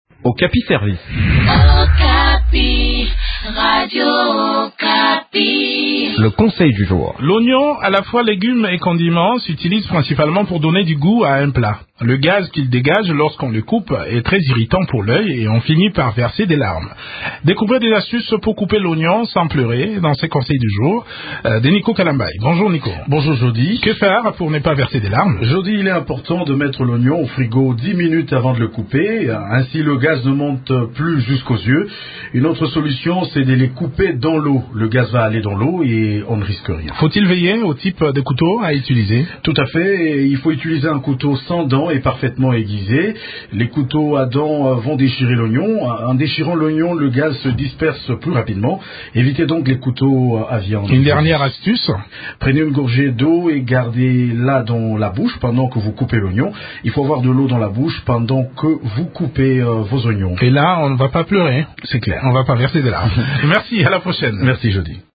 Le gaz qu’il dégage lorsqu’on le coupe est très irritant pour l’œil et on finit par verser des larmes. Découvrez des astuces pour couper l’oignon sans pleurer dans cette chronique